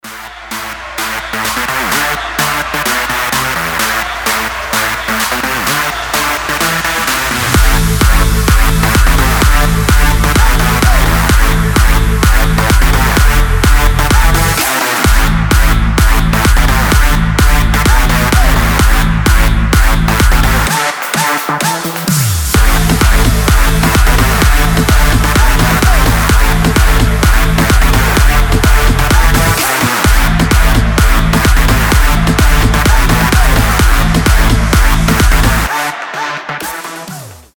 • Качество: 320, Stereo
жесткие
мощные
EDM
взрывные
энергичные
очень громкие
Стиль: electro house